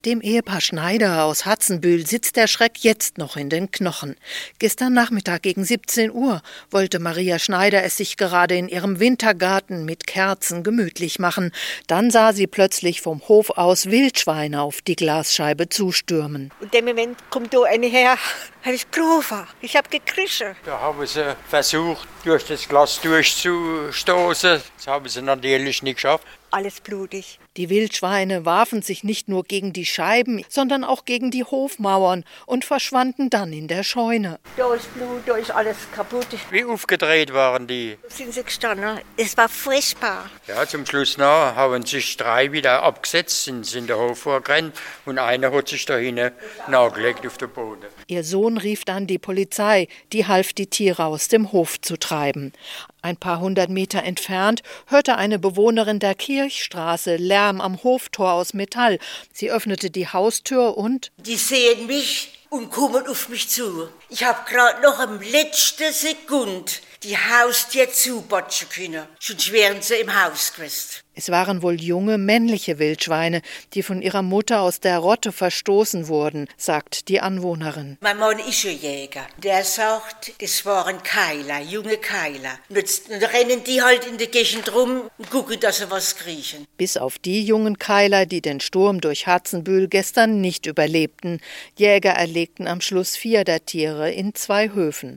Wie Bewohner die randalierenden Wildschweine in Hatzenbühl erlebt haben